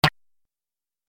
دانلود آهنگ ربات 44 از افکت صوتی اشیاء
جلوه های صوتی
دانلود صدای ربات 44 از ساعد نیوز با لینک مستقیم و کیفیت بالا